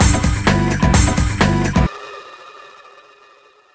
Orgn Brk 128-G.wav